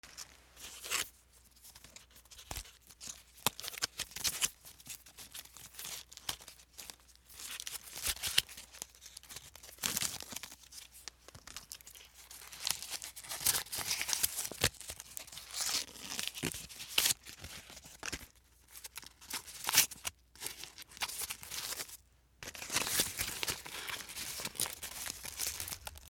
笹団子をむく
/ M｜他分類 / L01 ｜小道具
U87Ai